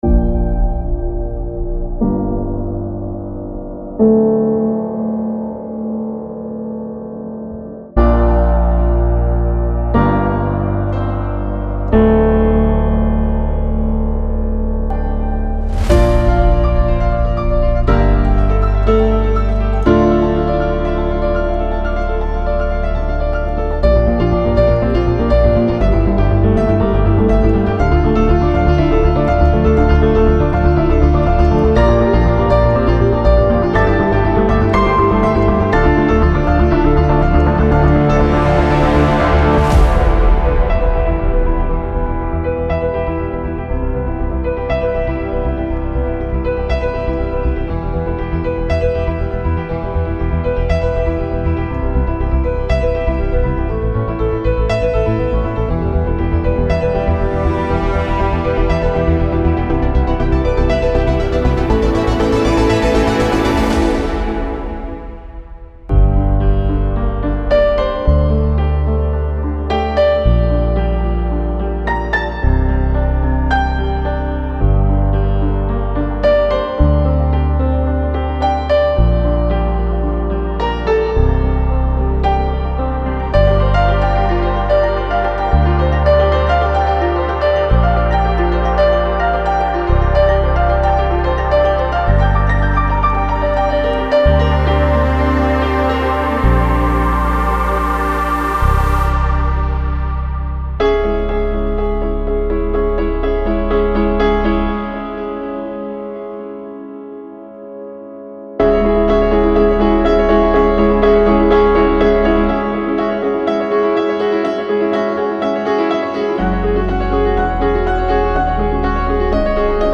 Genre:Cinematic
感情、タイミング、フィーリングが織り込まれた、アコースティックピアノによるリアルな演奏から生まれたコレクションです。
165 Synth Loops
66 Piano Loops